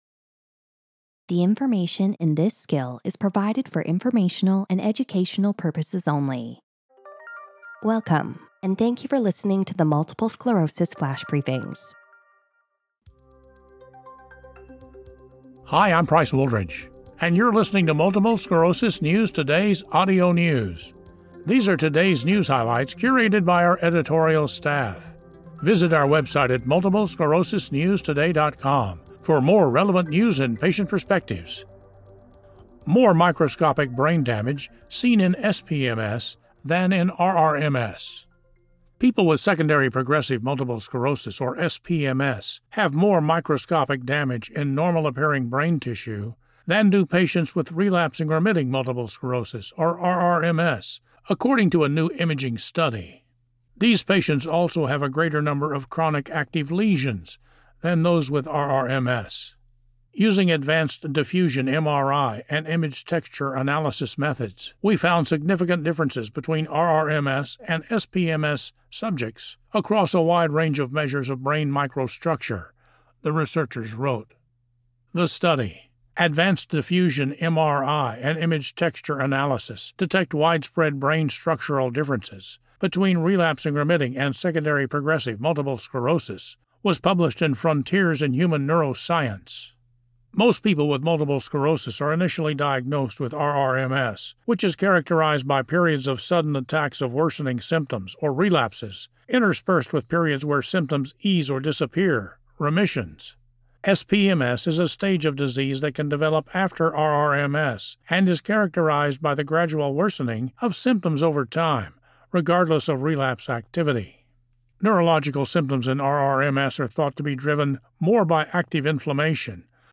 reads a news article on how six months of treatment with foralumab nasal spray led to significant functional improvements in the second patient with non-active SPMS.